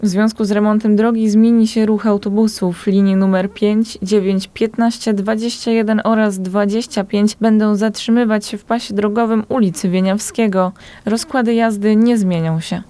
Dnia 24 sierpnia, czyli w najbliższy czwartek przeprowadzana będzie naprawa nawierzchni ulicy przy przystanku „Pętla Chopina”. Więcej na ten temat wie nasza reporterka